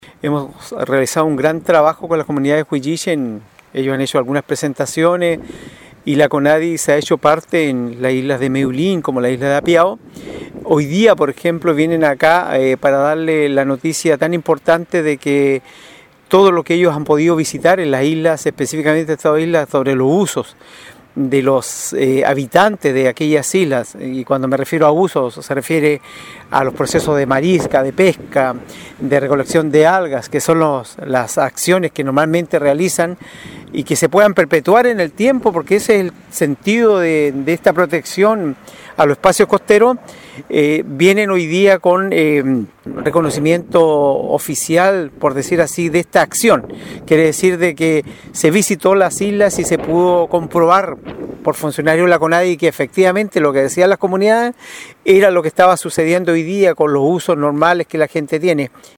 Por su parte, el alcalde René Garcés puntualizó que han realizado un gran trabajo:
CUNA-3-ALCALDE-RENE-GARCES-2.mp3